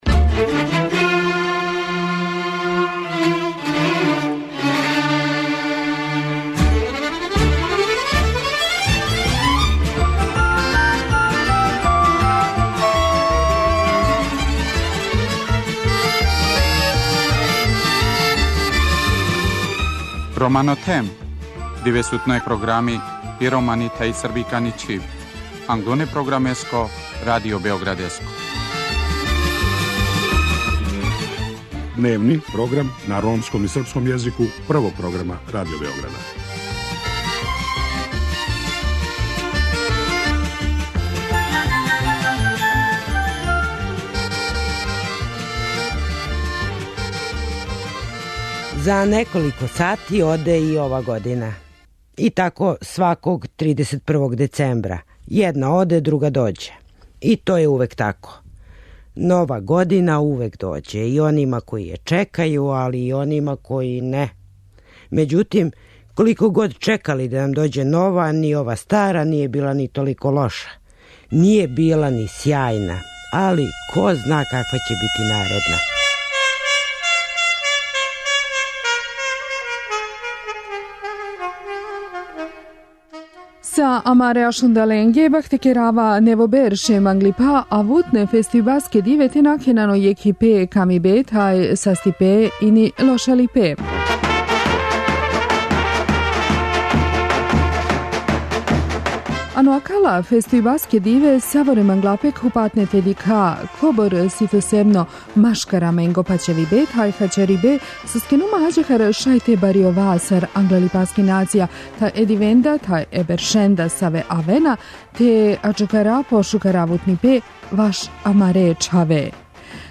У специјалном, предноводишњем издању Света Рома, чућете честитке које су слушаоцима упутили чланови наше редакције. Очекује вас пуно добре музике и још боље забаве у последњем овогодишњем издању емисије.